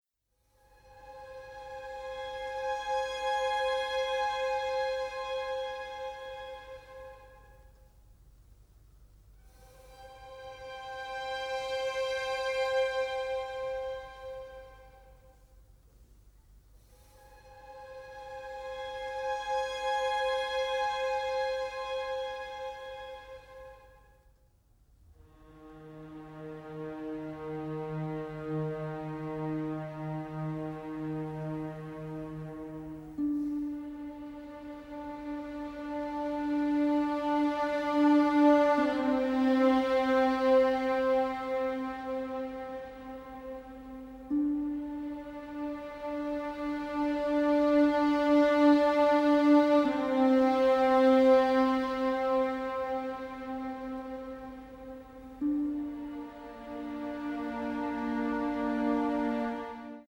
a harrowing, incredibly emotive score
Recorded at Abbey Road Studios